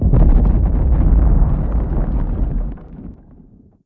explo5_deep.wav